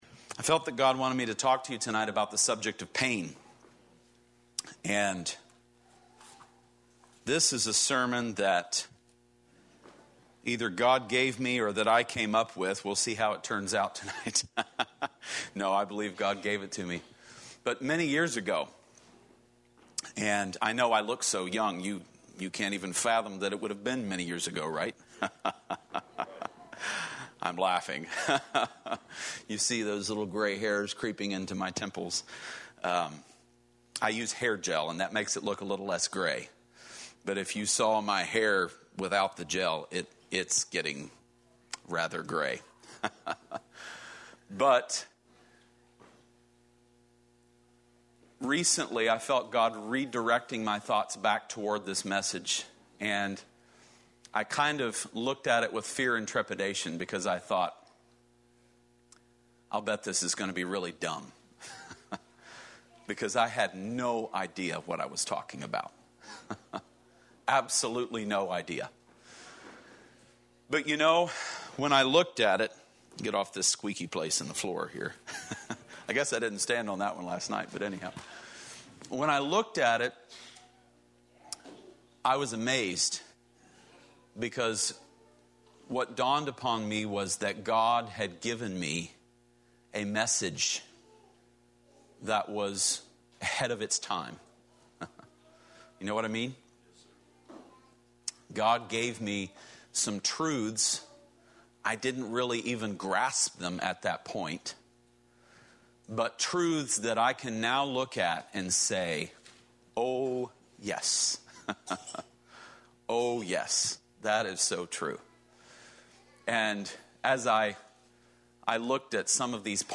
A sermon
Series: Youth Revival 2021